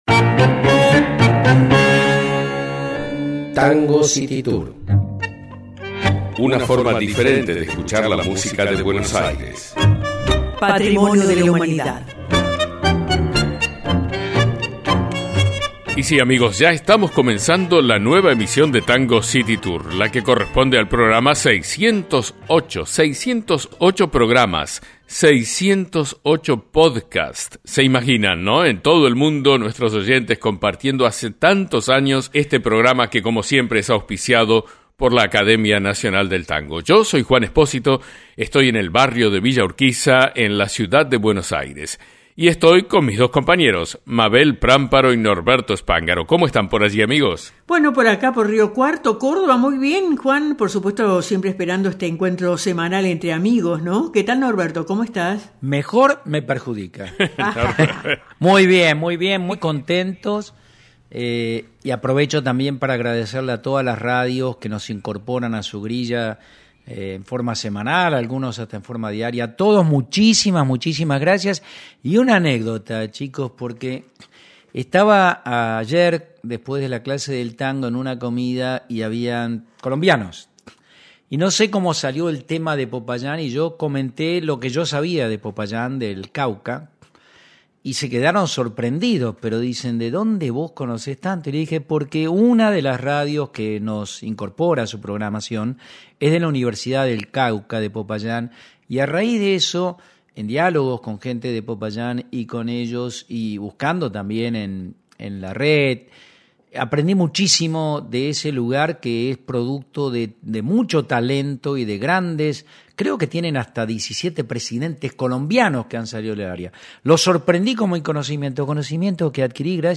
Tango city tour: poesía y bandoneón 0 comentarios El tango es un género pasional.
tango-608.mp3